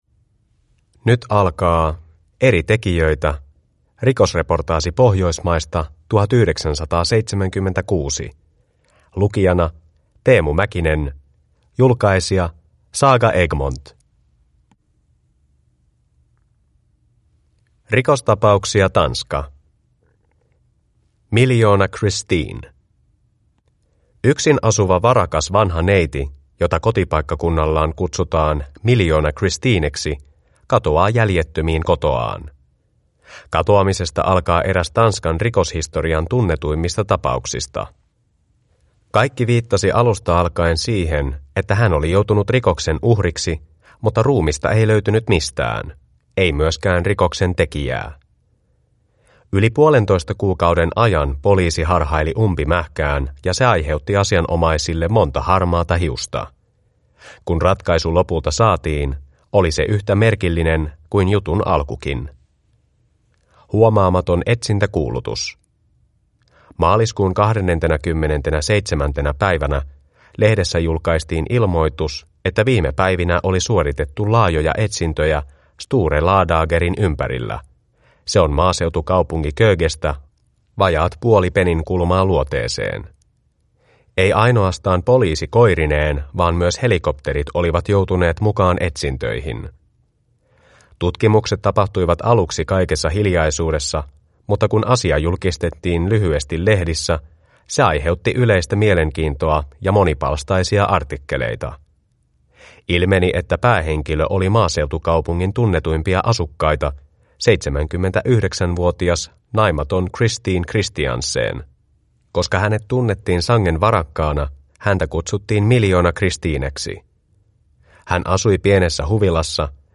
Rikosreportaasi Pohjoismaista 1976 (ljudbok) av Eri tekijöitä